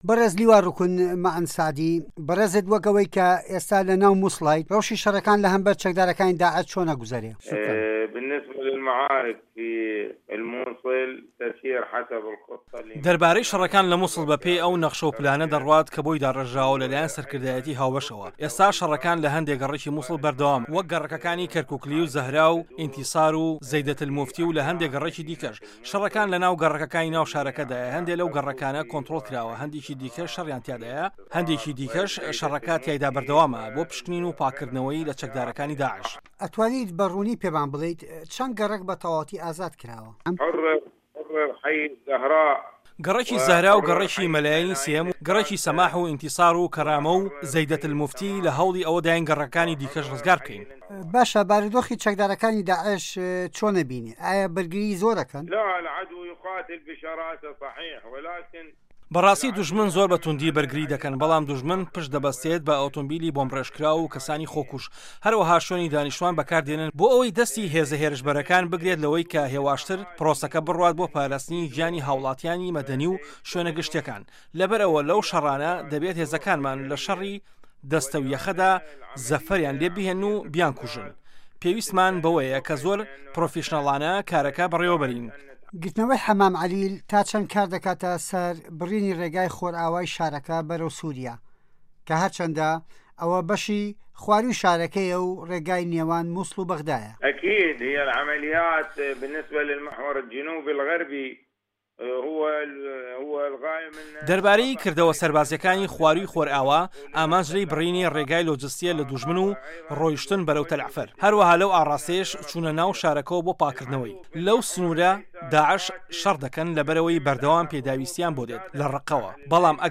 وتووێژ لەگەڵ لیوا روکن مه‌عه‌ن ئه‌لسه‌عدی